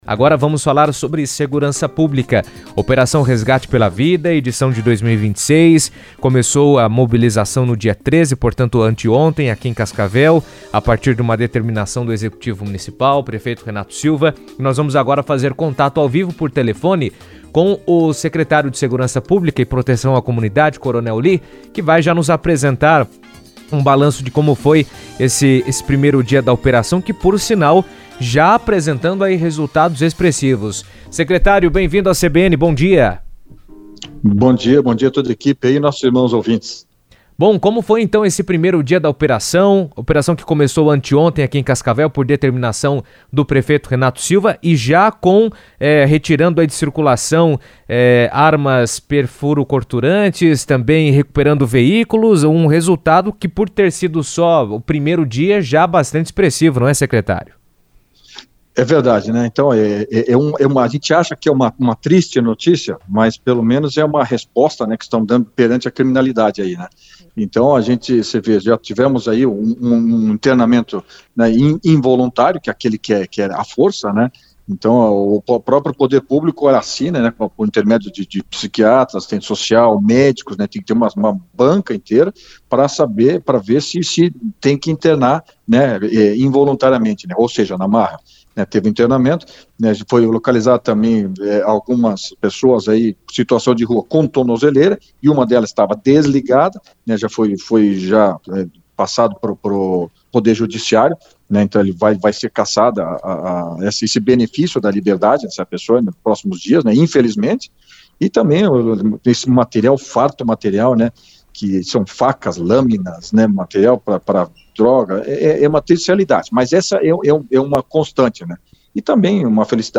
A Secretaria de Segurança Pública divulgou os primeiros resultados da Operação Resgate pela Vida, destacando ações de internamento involuntário, recuperação de veículos e retirada de objetos perfurocortantes das ruas. O Coronel Lee, secretário de Segurança Pública, comentou sobre os números e detalhou os objetivos da operação durante entrevista à CBN.